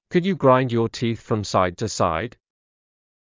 ｸｯｼﾞｭｰ ｸﾞﾗｲﾝﾄﾞ ﾕｱ ﾃｨｰｽ ﾌﾛﾑ ｻｲﾄﾞ ﾄｩ ｻｲﾄﾞ